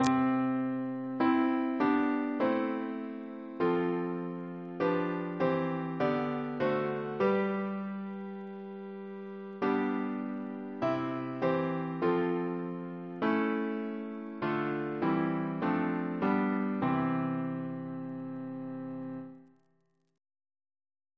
Double chant in C Composer: Henry G. Ley (1887-1962) Reference psalters: ACB: 328; RSCM: 89